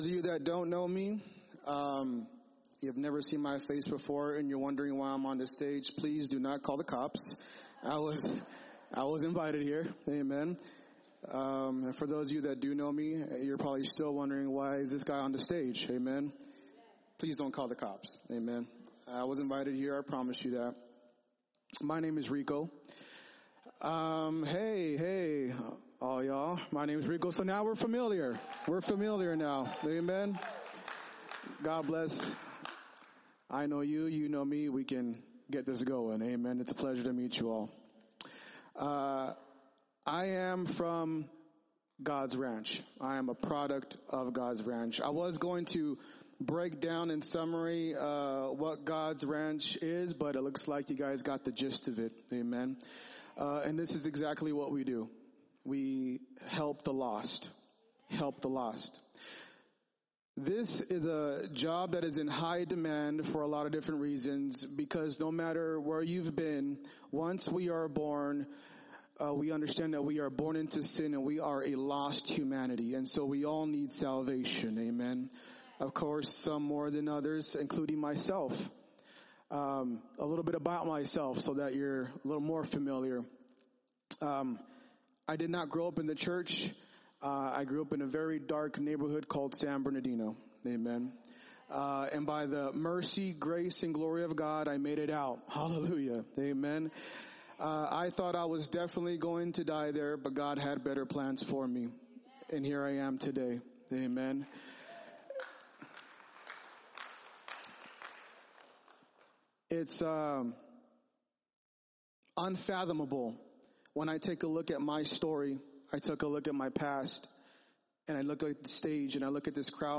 freecompress-Gods-Ranch-Sermon-7-20-25.mp3